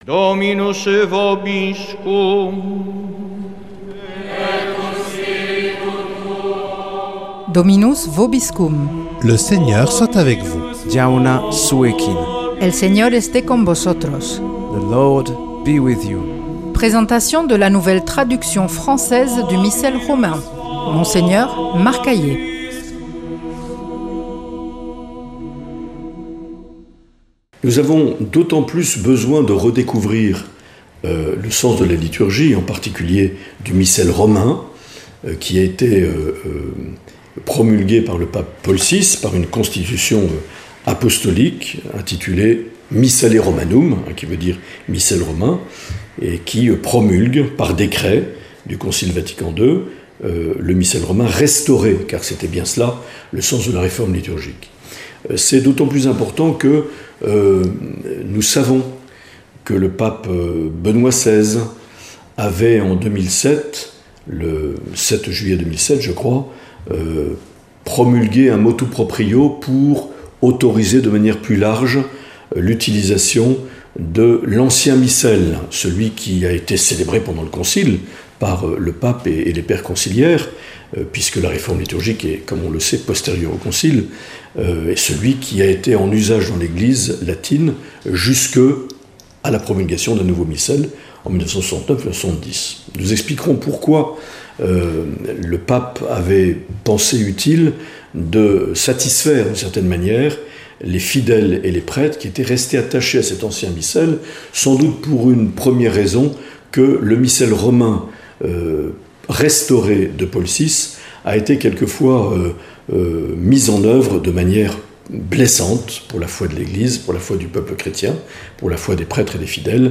Présentation de la nouvelle traduction française du Missel Romain par Mgr Marc Aillet